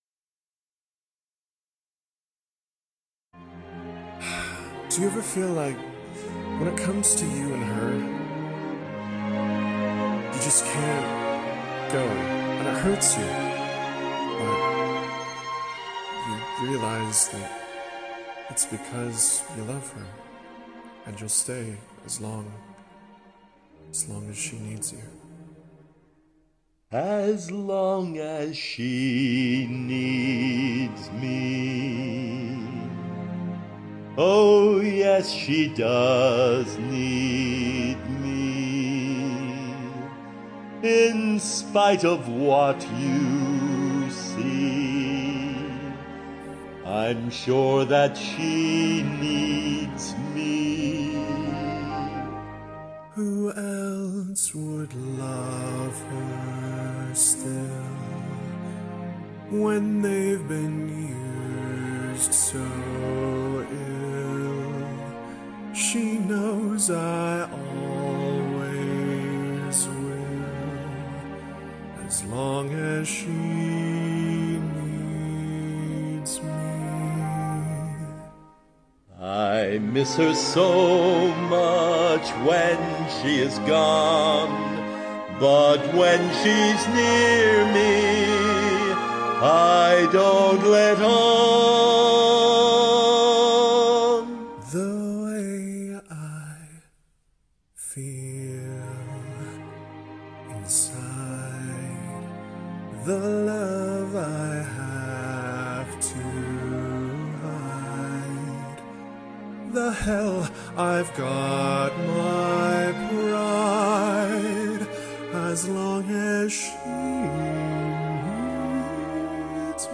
cover
duet
harmony